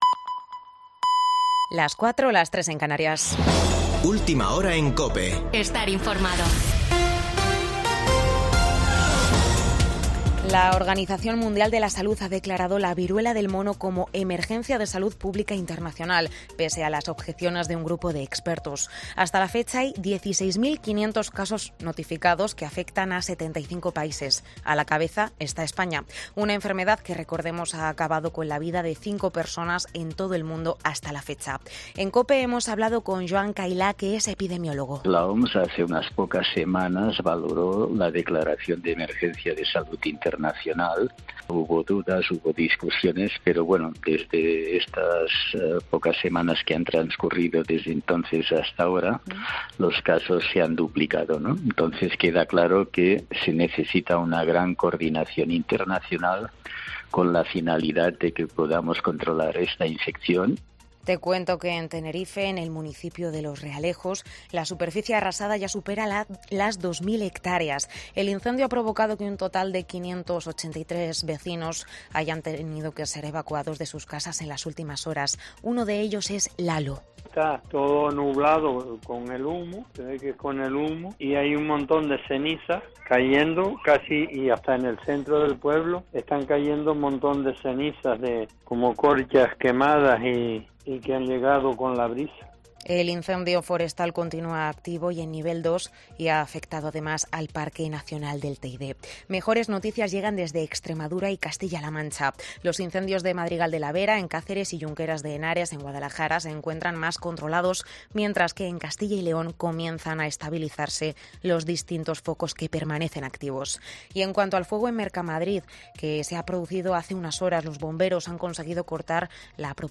AUDIO: Boletín de noticias de COPE del 24 de julio de 2022 a las 04:00 horas